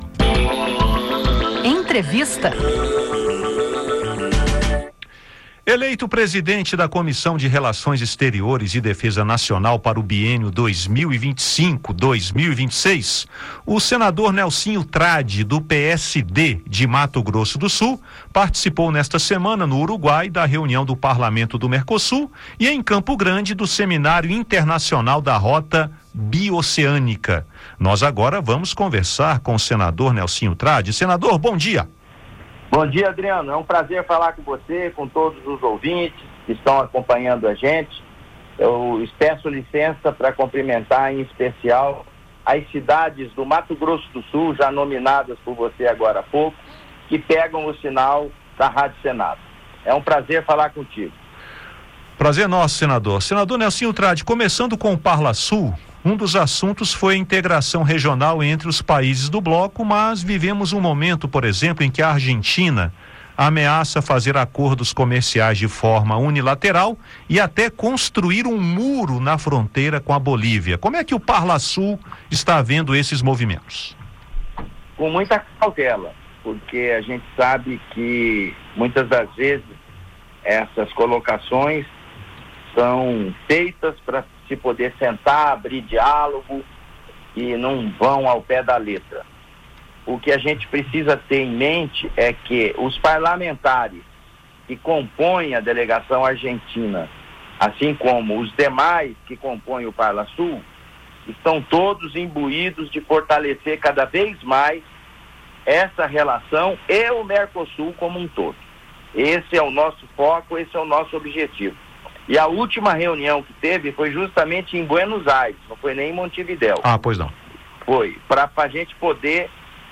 O senador falou ao Conexão Senado sobre as relações entre os países do Mercosul e os desafios de implantação da Rota Bioceânica, que ligará Mato Grosso do Sul ao Oceano Pacífico. Trad também destacou as expectativas de ações à frente da CRE, em meio a mudanças no cenário internacional.